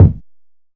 DillaChudKick.wav